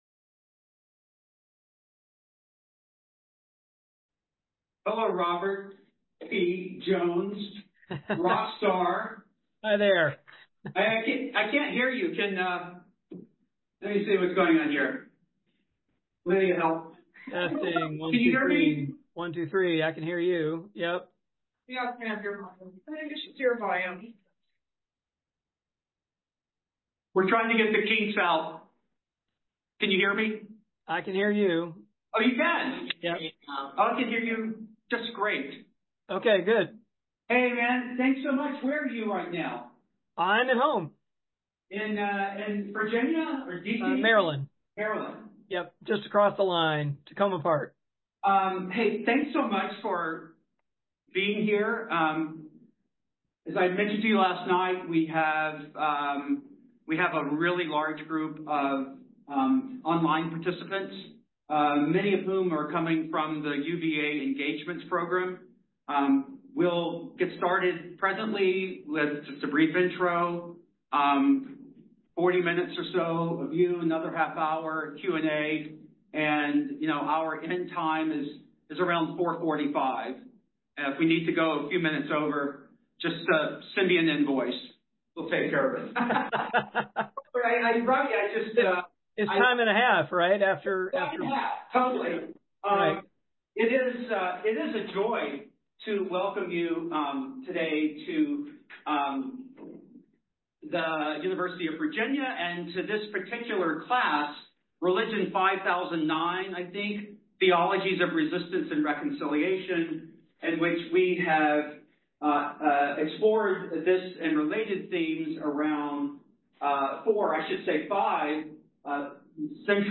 On December 3, 2024, Robert P. Jones joined the UVA graduate seminar Theologies of Resistance and Reconciliation by Zoom to talk about his research on the theological and historical sources of white supremacy in the United States.